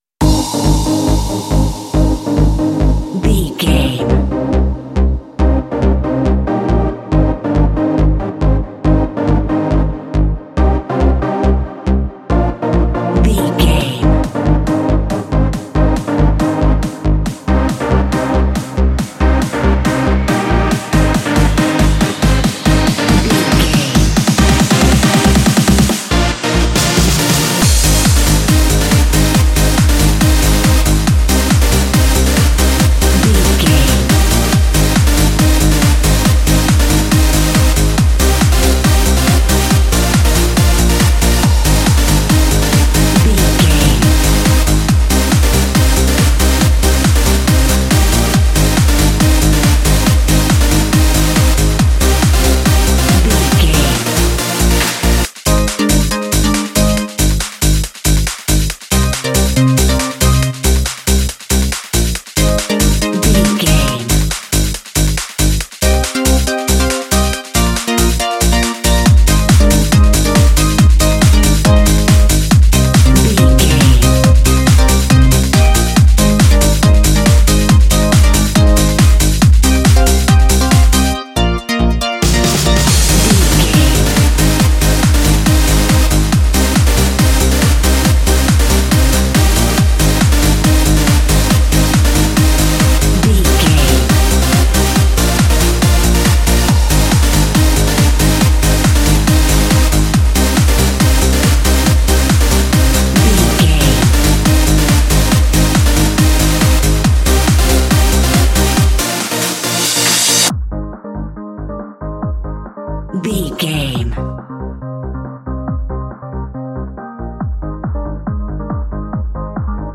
Modern Top 40 Chart Trance.
Aeolian/Minor
Fast
groovy
uplifting
driving
energetic
repetitive
synthesiser
drum machine
acid trance
uptempo
synth leads
synth bass